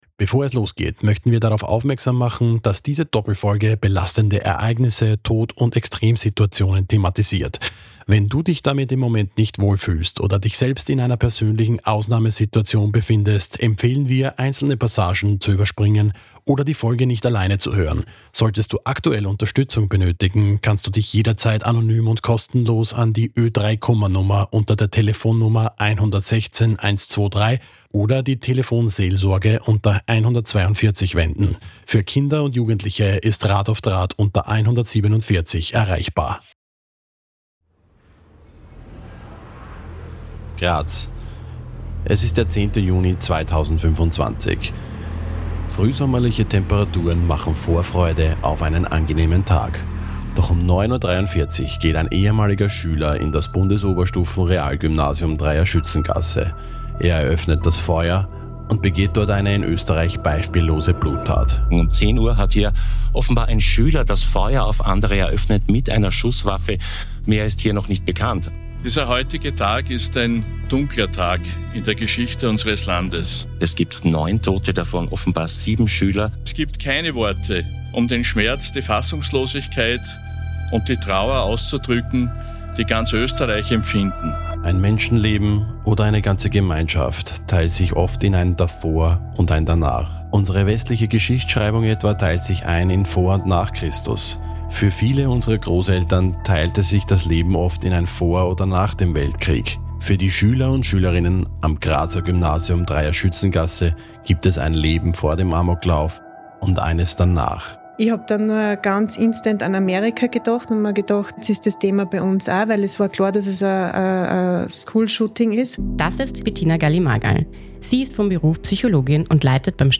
Ausgehend vom Grubenunglück in Lassing und der Lawinenkatastrophe in Galtür schlagen wir den Bogen bis in die Gegenwart nach Graz. Wir sprechen mit Zeitzeug:innen, Überlebenden, Helfer:innen und Entscheidungsträger:innen über das Erlebte – und über die oft unsichtbare Phase danach.